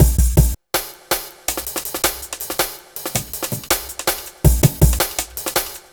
Dinky Break 03-162.wav